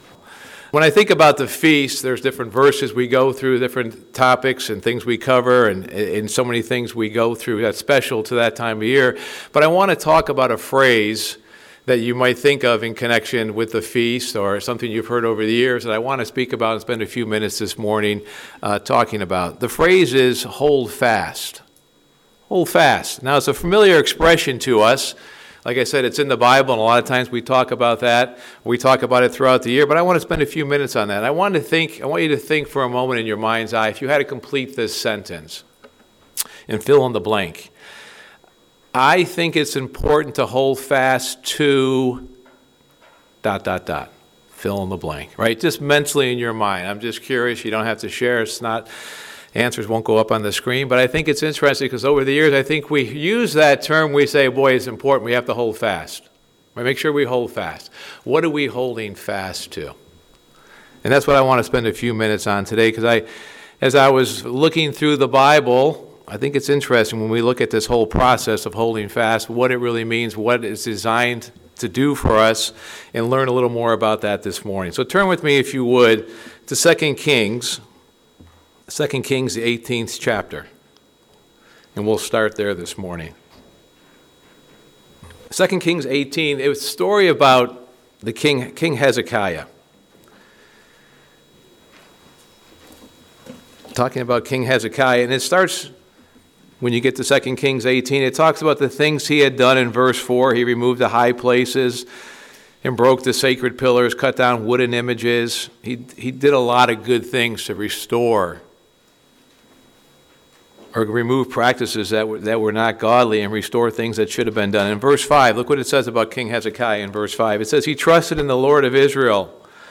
Sermon
Given in Ft. Wayne, IN